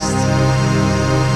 CHRDPAD039-LR.wav